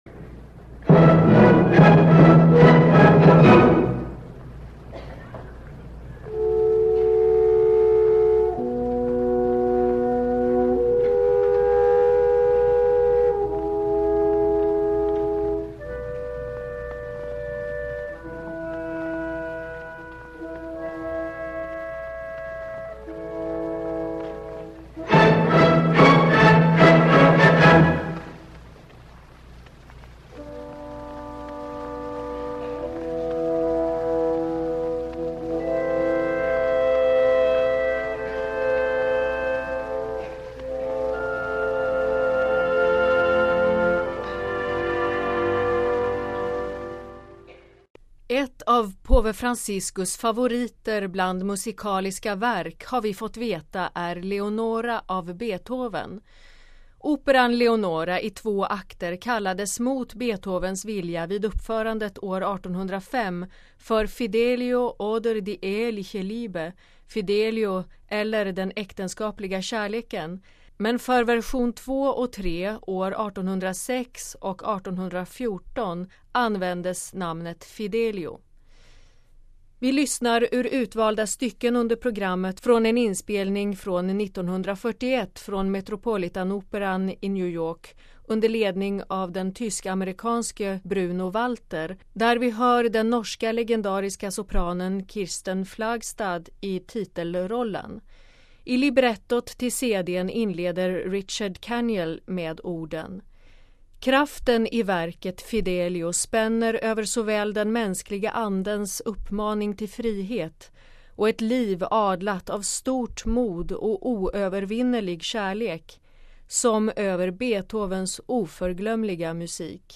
Vi lyssnar ur utvalda stycken under programmet från en inspelning från 1941 från Metropolitanoperan i New York under ledning av den tysk-amerikanske Bruno Walter där vi hör den norska legendariska sopranen Kirsten Flagstad i titelrollen.
Här hör vi fångarnas kör ”O welche Lust..” ungefär ”Å så skönt”: